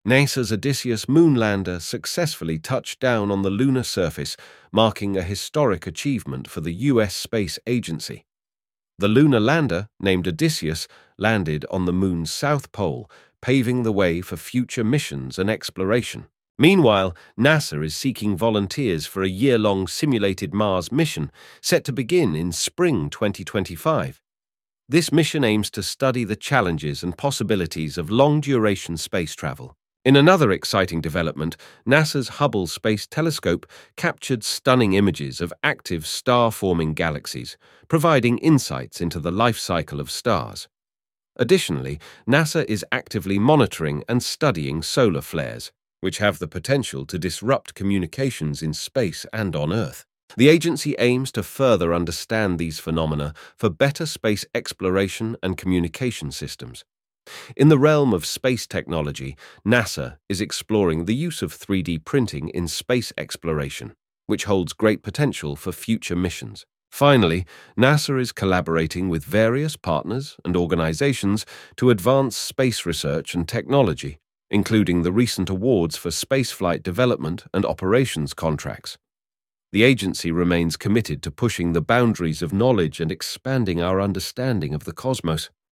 Automatically create personalized podcasts with artificial intelligence
Realistic Voices